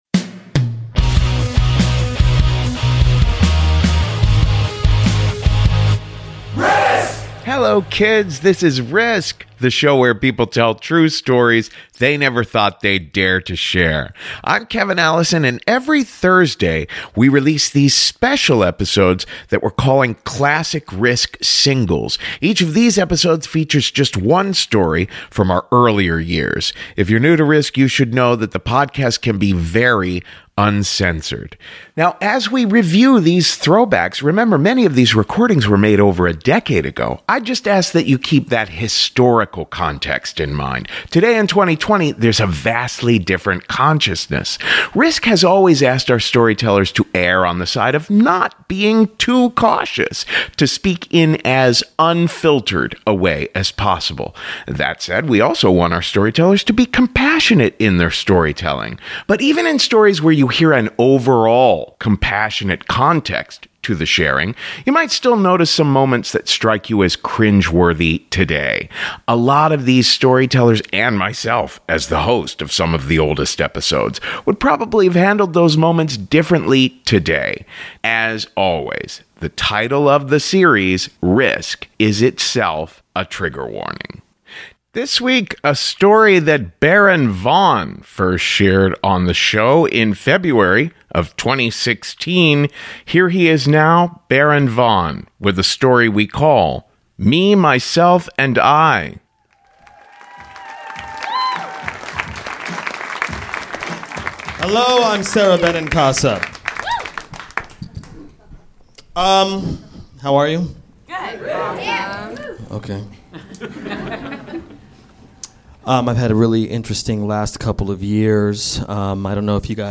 A story that Baron Vaughn first shared on the podcast in February of 2016 about a memorable ayahuasca trip that turned shitty.